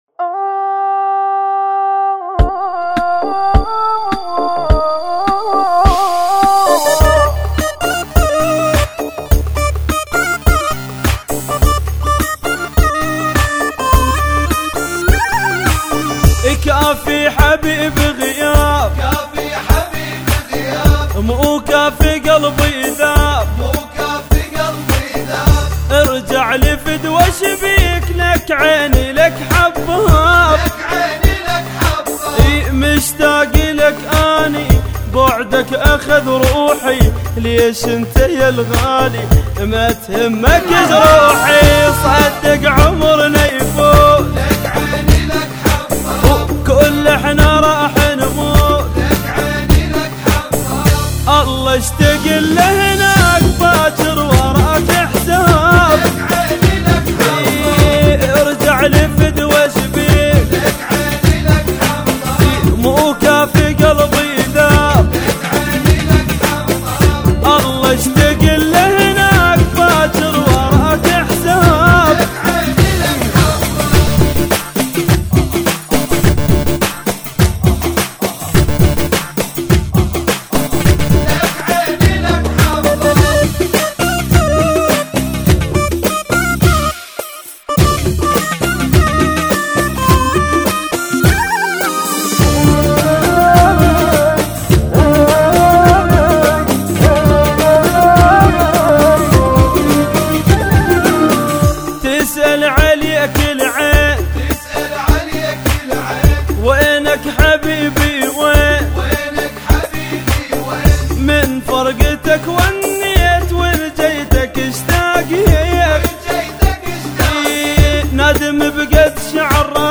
ترانه عراقی